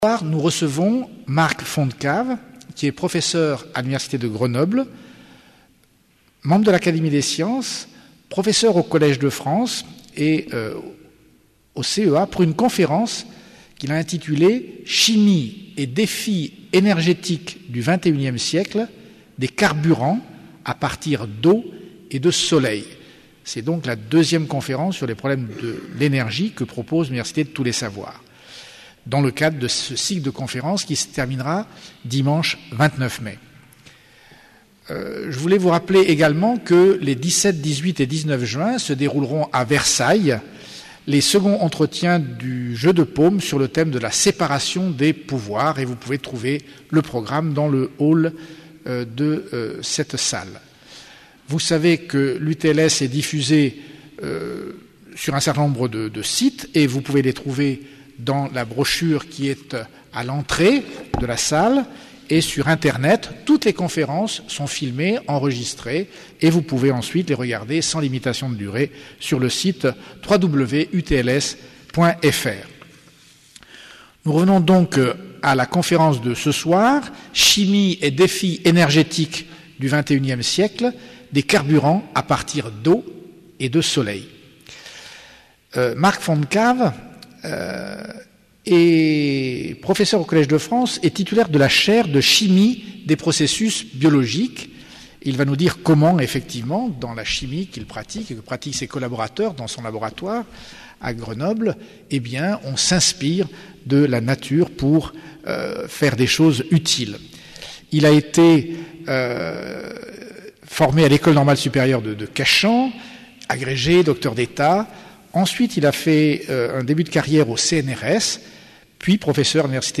Une conférence UTLS